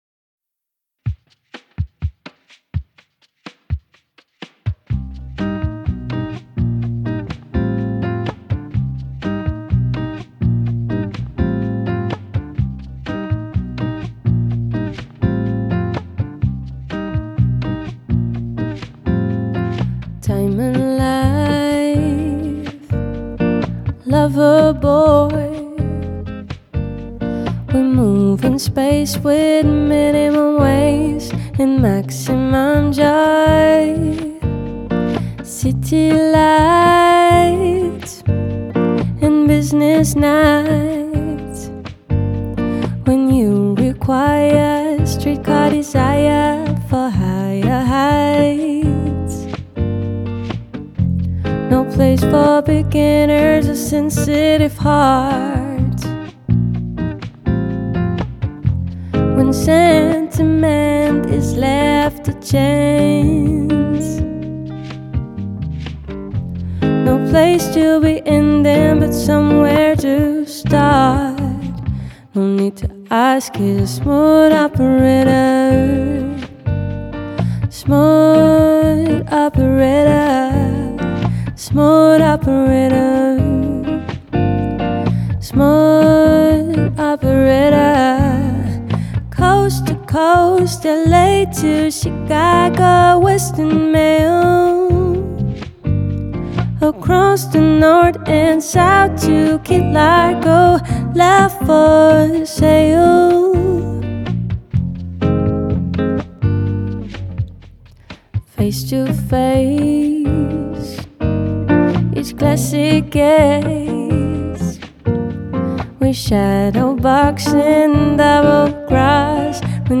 Soul, pop : des classiques revisités avec style